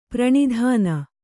♪ praṇidhāna